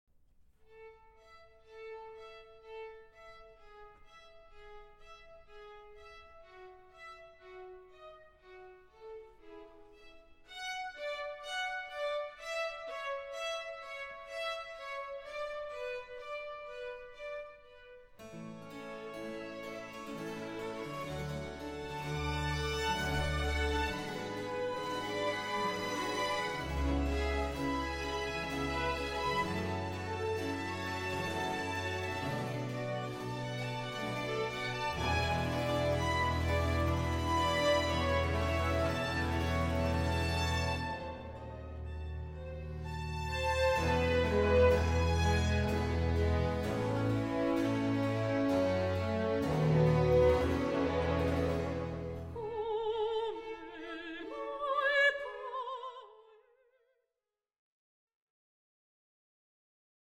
mezzo-soprano
baroque repertoire
secular cantatas